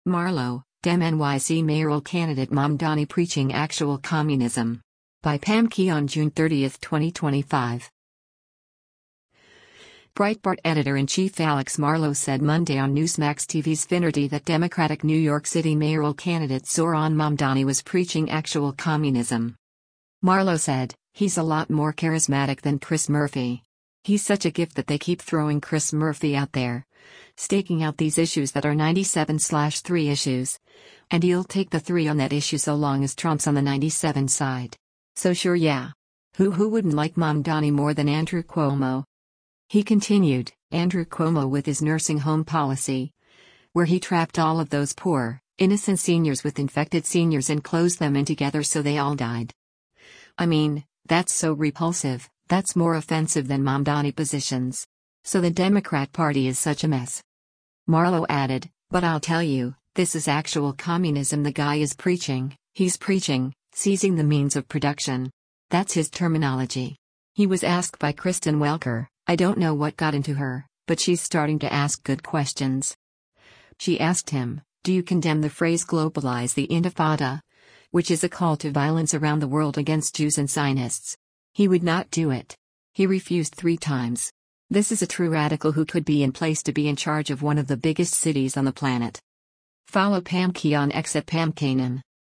Breitbart Editor-in-Chief Alex Marlow said Monday on Newsmax TV’s “Finnerty” that Democratic New York City mayoral candidate Zohran Mamdani was preaching “actual communism.”